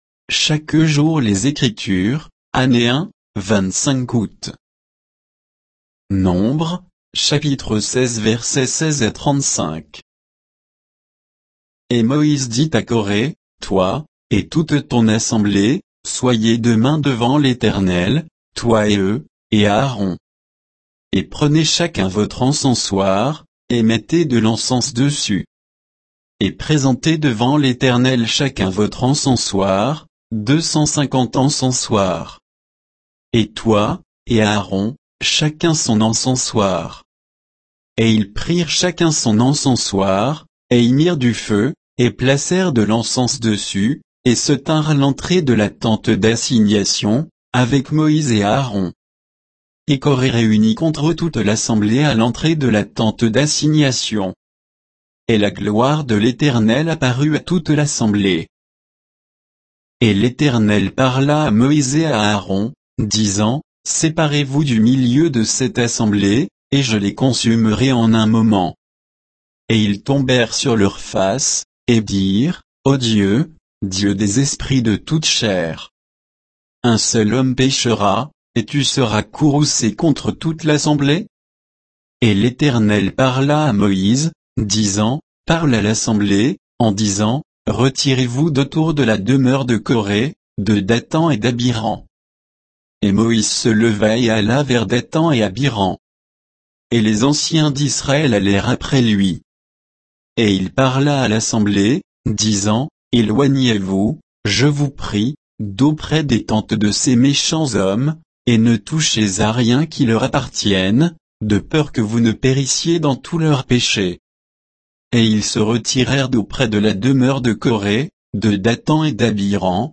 Méditation quoditienne de Chaque jour les Écritures sur Nombres 16